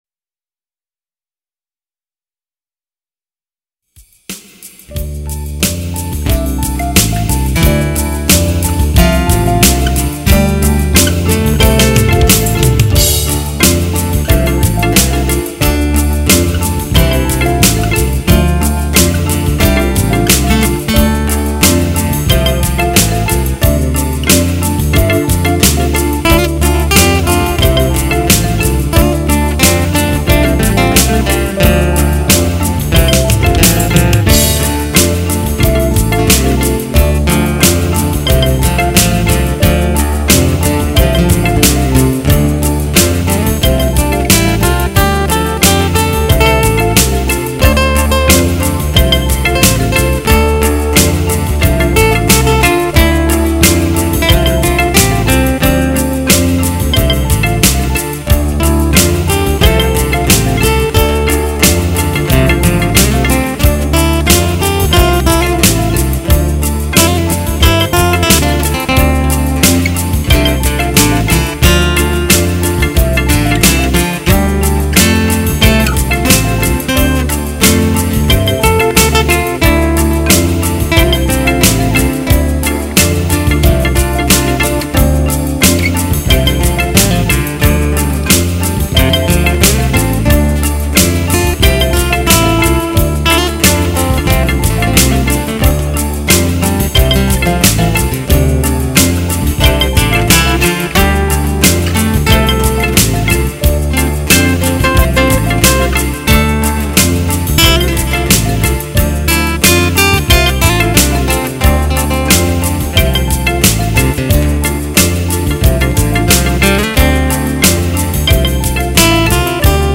ORIGINAL INSTRUMENTALS
SMOOTH SIMPLE JAZZ TUNES